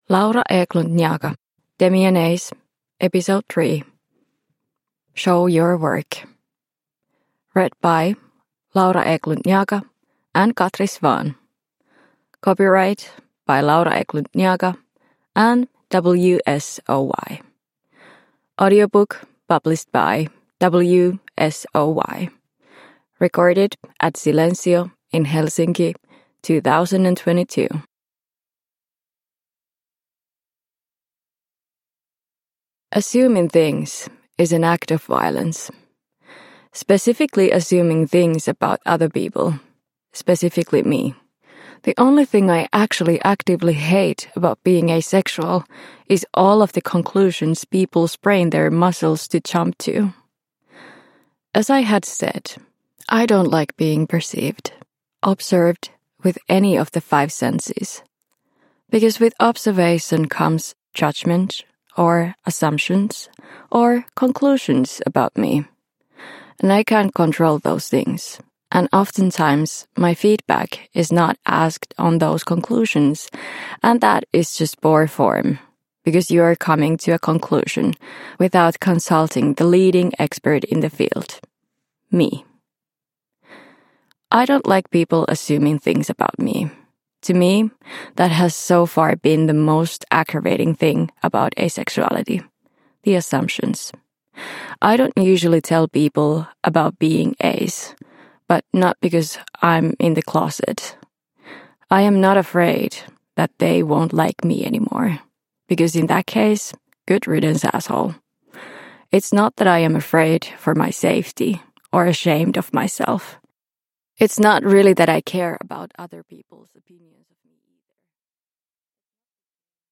A funny and moving audio series about how weird love is.